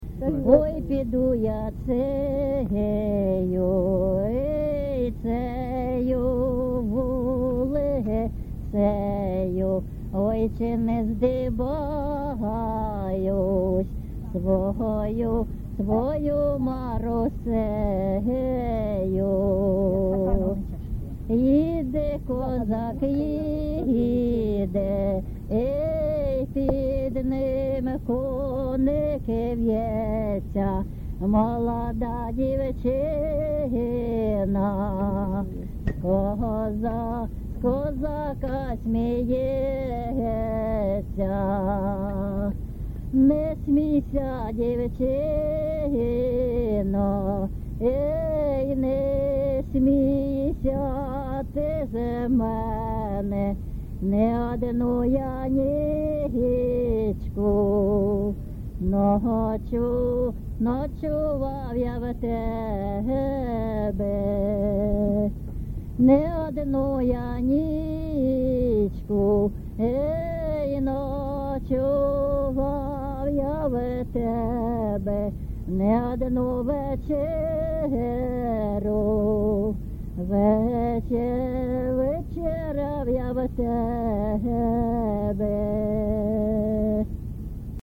ЖанрПісні з особистого та родинного життя
Місце записус. Богородичне, Словʼянський район, Донецька обл., Україна, Слобожанщина